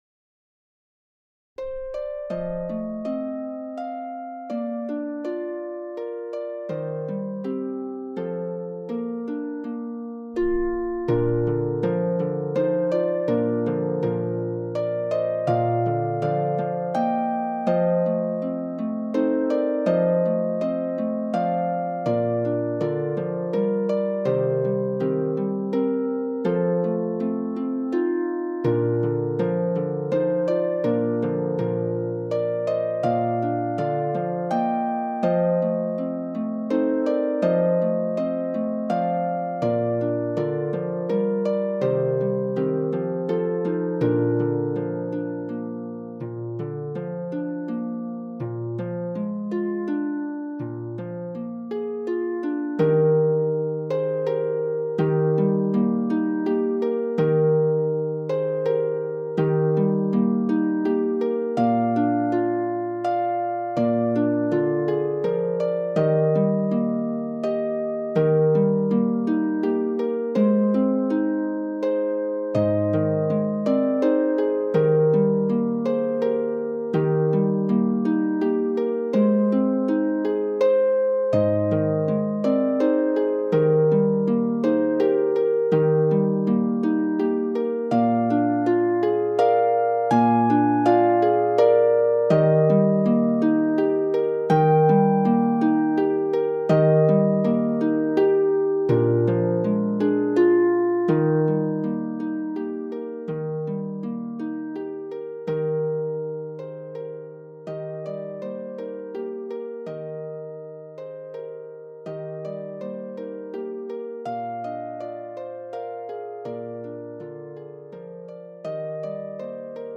is for four lever or pedal harps